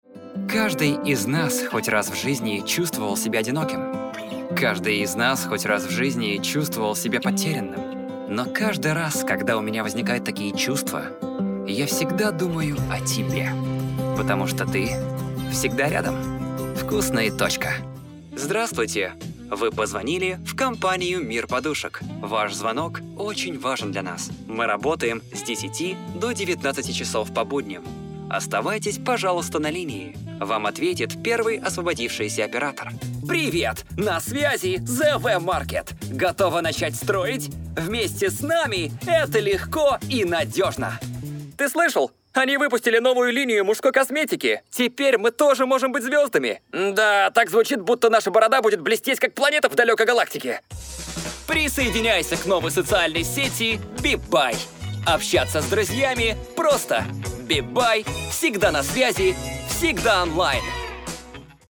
Rode NT1-A, focusrite scralett solo 2nd gen, Cubase 11 pro.